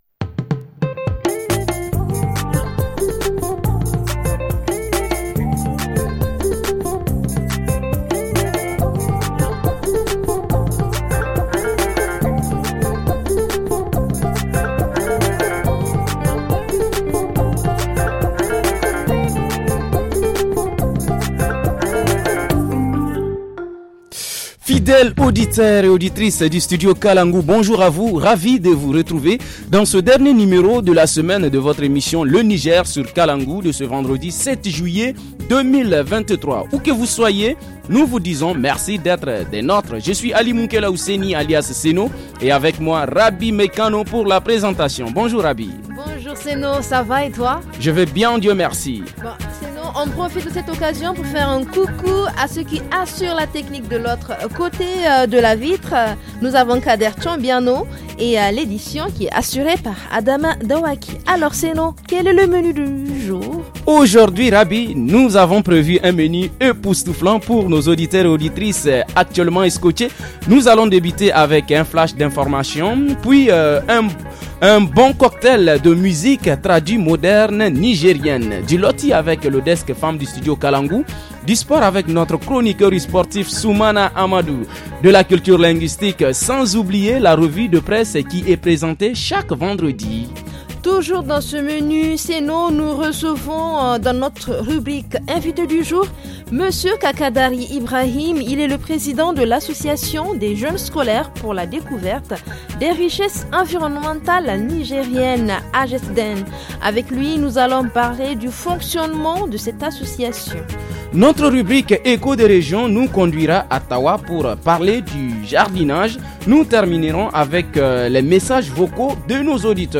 – Reportage en Région : Activité de jardinage à Tahoua.